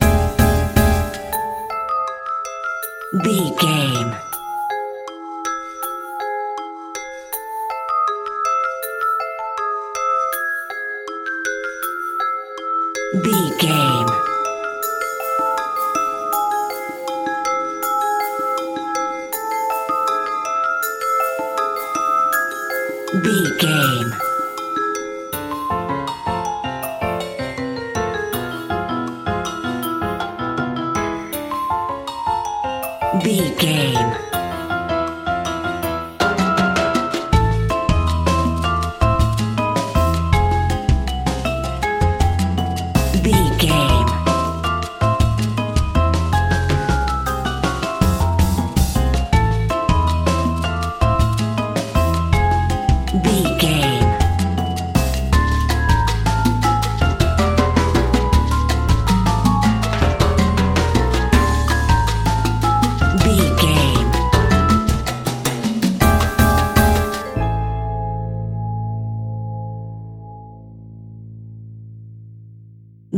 Aeolian/Minor
percussion
silly
circus
goofy
comical
cheerful
perky
Light hearted
secretive
quirky